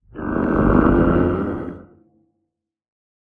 Minecraft Version Minecraft Version snapshot Latest Release | Latest Snapshot snapshot / assets / minecraft / sounds / mob / warden / agitated_1.ogg Compare With Compare With Latest Release | Latest Snapshot
agitated_1.ogg